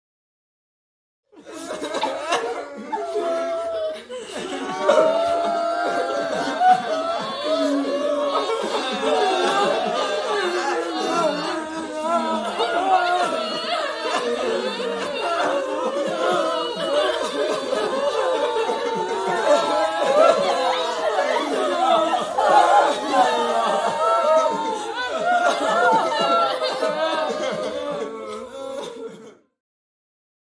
دانلود آهنگ گریه دسته جمعی از افکت صوتی انسان و موجودات زنده
دانلود صدای گریه دسته جمعی از ساعد نیوز با لینک مستقیم و کیفیت بالا
جلوه های صوتی